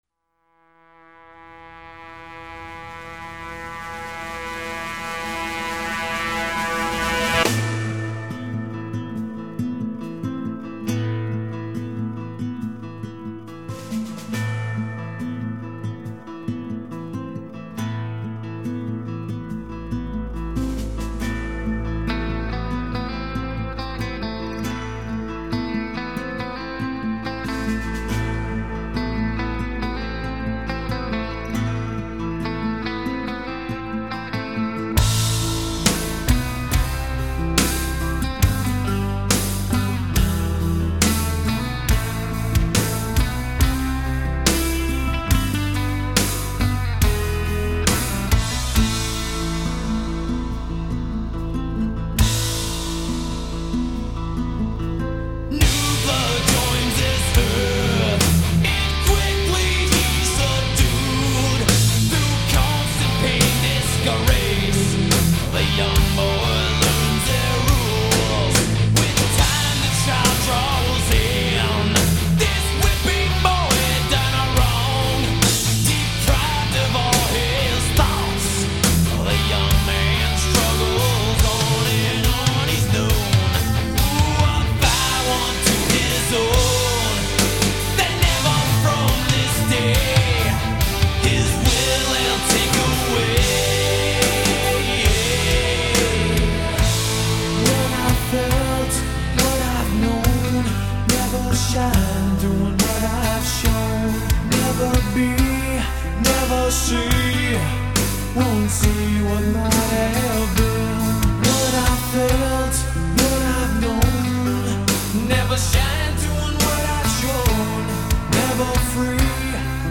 当重金属老将把狂躁、愤怒的激情沉淀下来，用最朴素最真实的声音唱出的柔情歌曲，那绝对是摇滚乐中的传世经典。